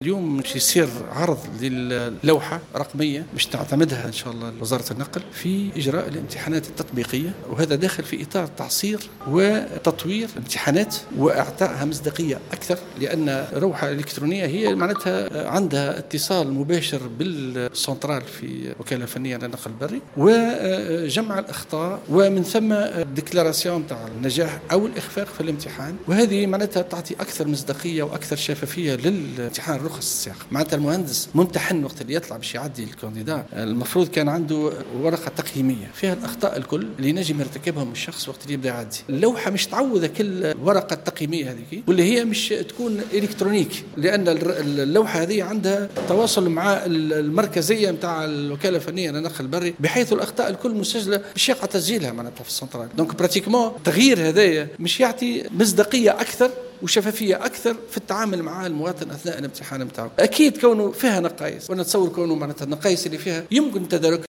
في تصريح للجوهرة أف أم على هامش إجتماع عام بمقر الإتحاد التونسي للصناعة والتجارة والصناعات التقليدية بسوسة